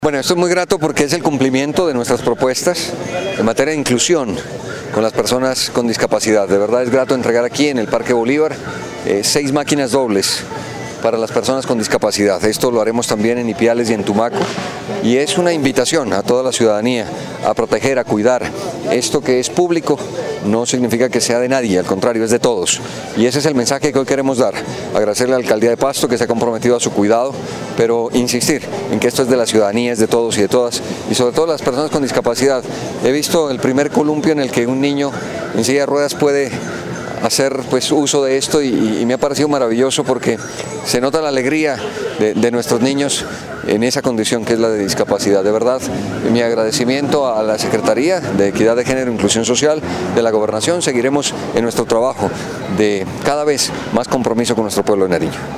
Entrevista al Gobernador, Camilo Romero
GOBERNADOR_DE_NARIO_-_CAMILO_ROMERO.mp3